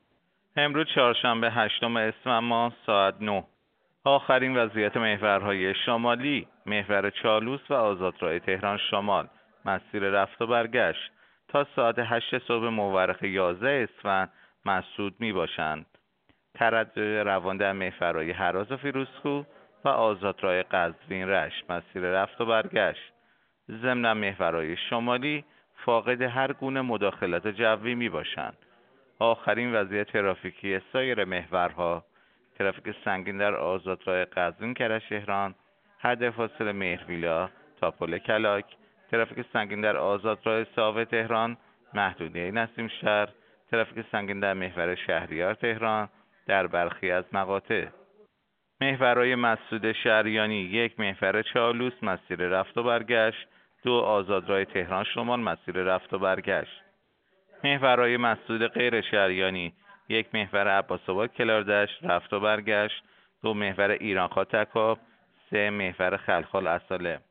گزارش رادیو اینترنتی از آخرین وضعیت ترافیکی جاده‌ها ساعت ۹ هشتم اسفند؛